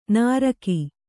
♪ nāraki